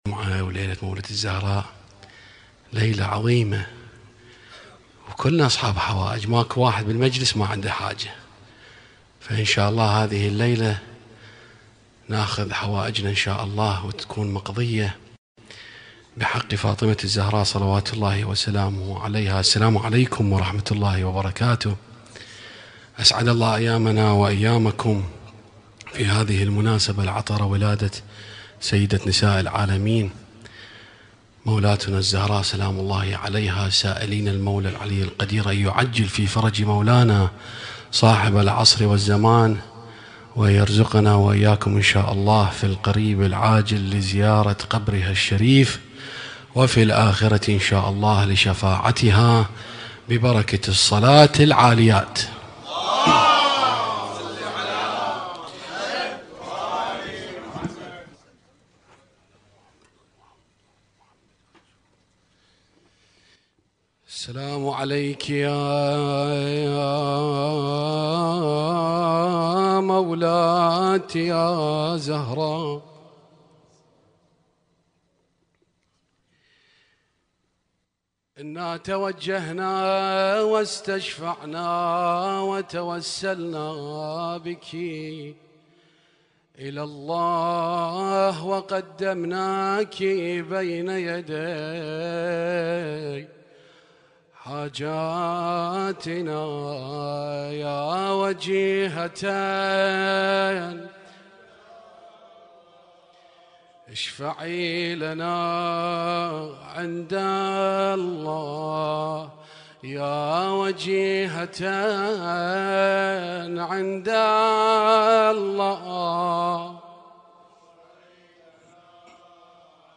Husainyt Alnoor Rumaithiya Kuwait
اسم النشيد:: مولد فاطمة الزهراء عليها السلام